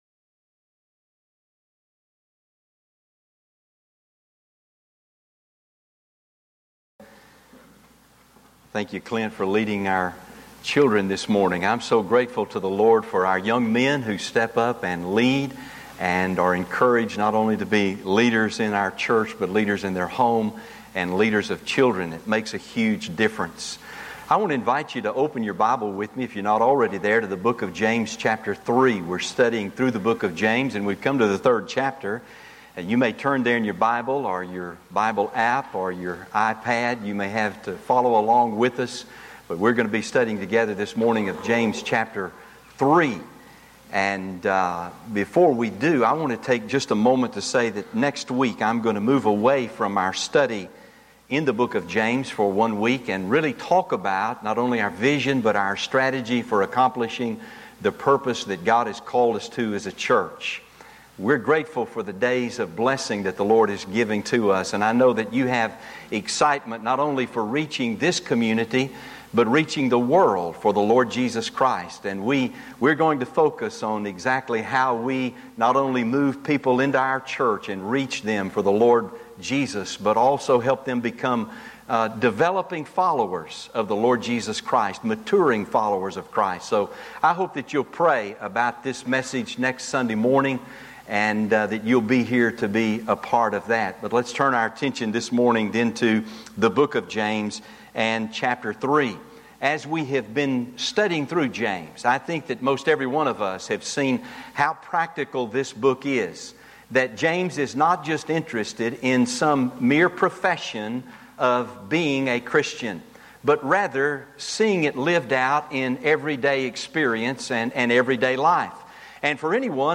First Baptist Church of Glen Rose Audio Sermons